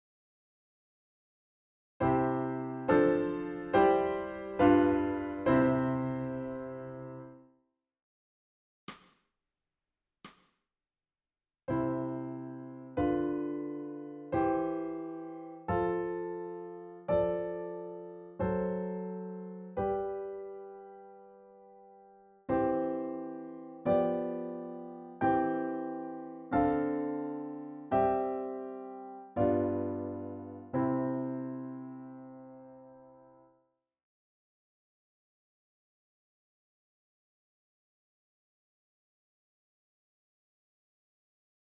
ソルフェージュ 聴音: 2-4-01